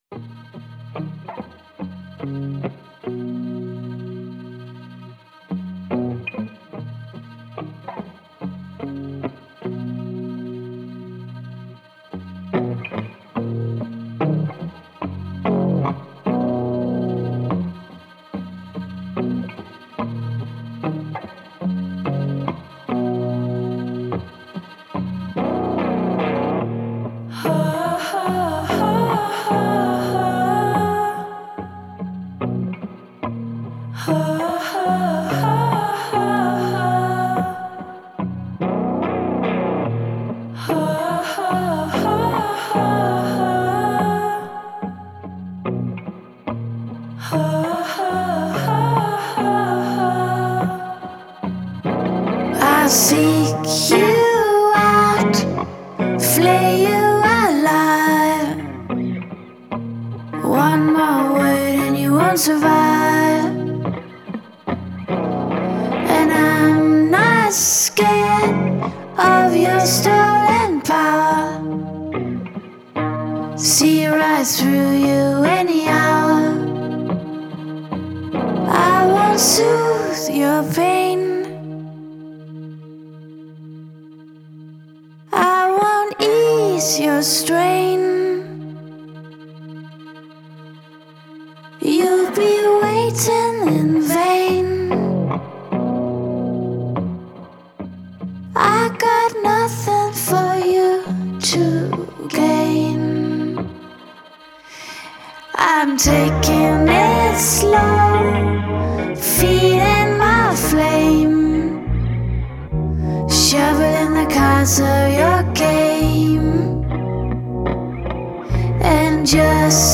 • Жанр: Electronic